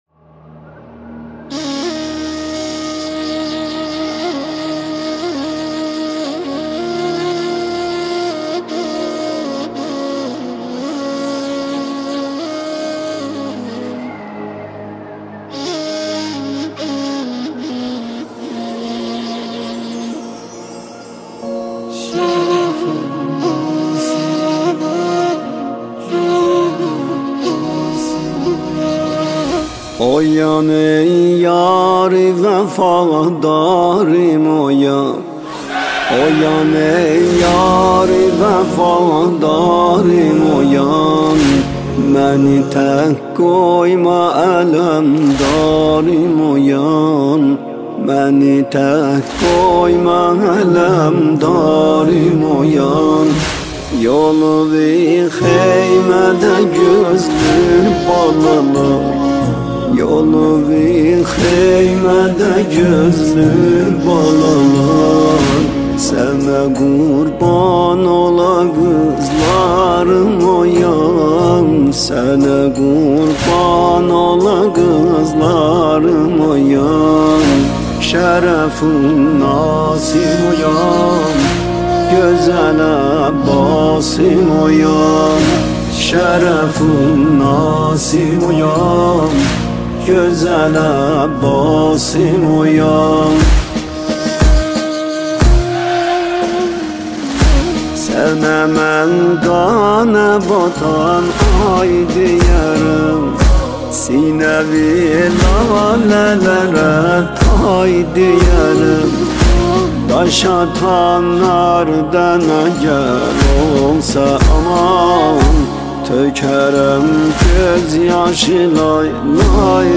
نماهنگ دلنشین ترکی
نماهنگ ترکی